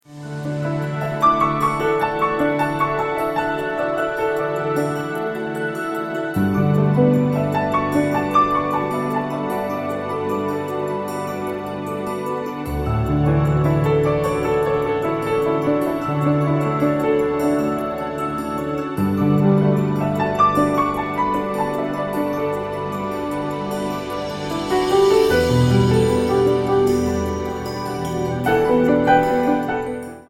78 BPM
Sprightly piano over orchestral strings and synthetic pads.